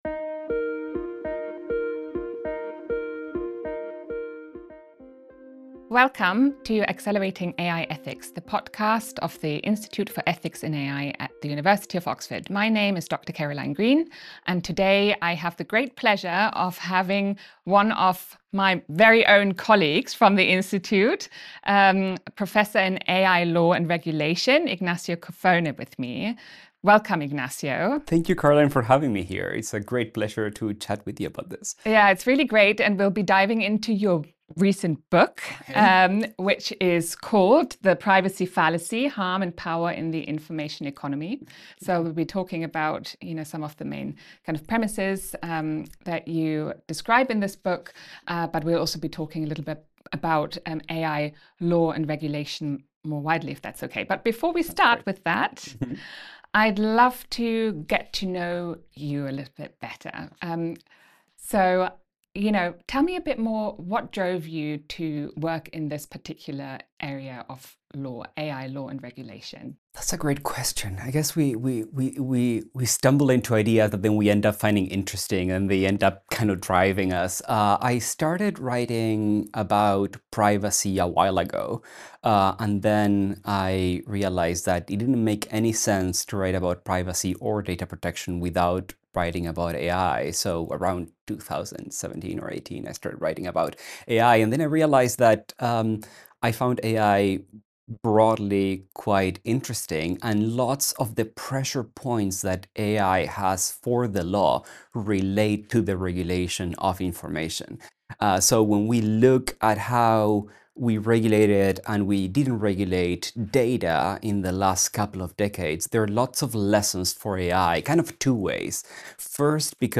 Throughout the conversation